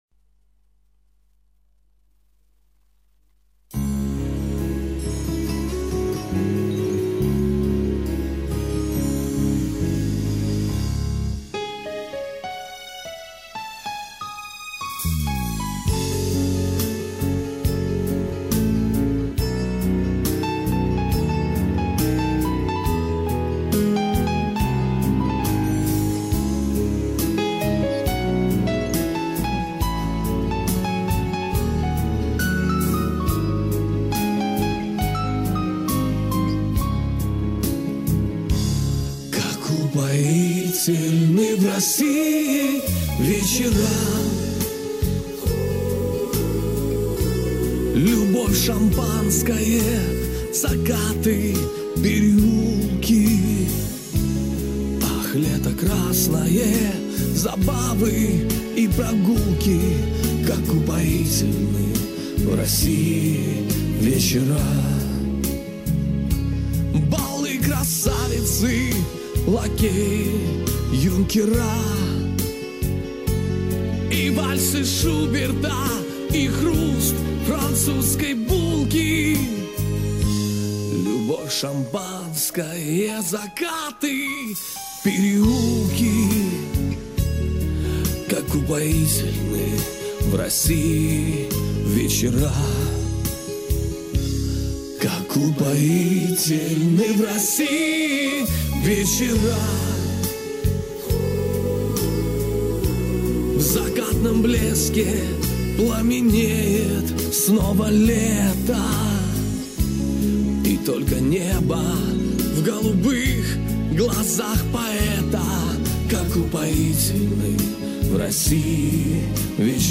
Есть другой вариант - студийная запись.